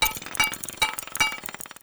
building_upgrade_1.wav